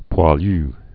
(pwä-lü)